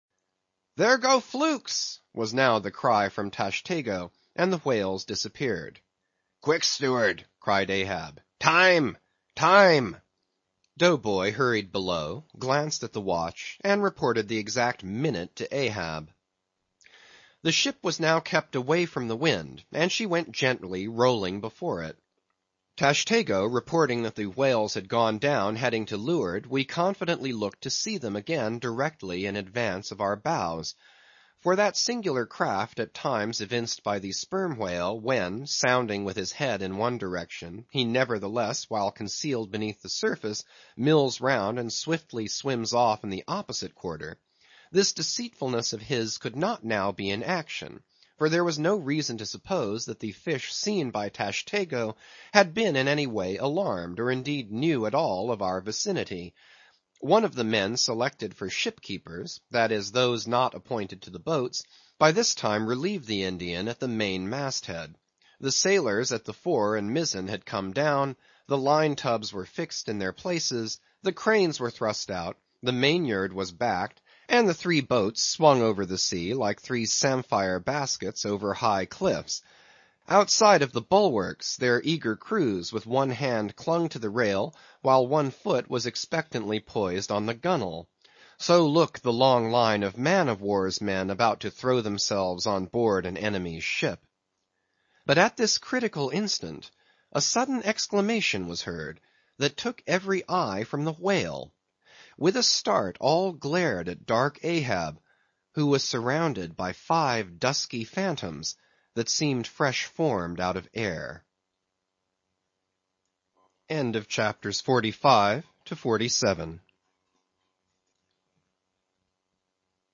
英语听书《白鲸记》第488期 听力文件下载—在线英语听力室